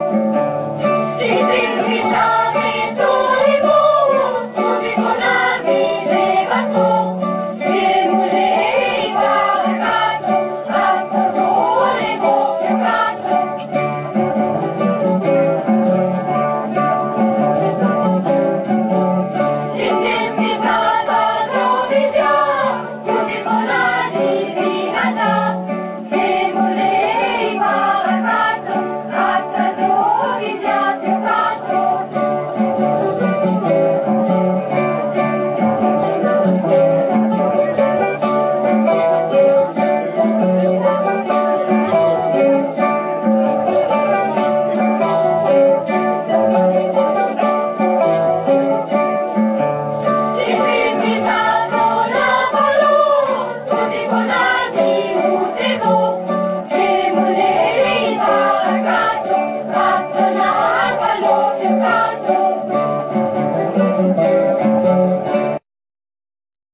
Συνεργασία νεανικής ορχήστρας  κιθάρων από το Αίγιον
με ελληνικές μελωδίες να τραγουδιώνται στα ελληνικά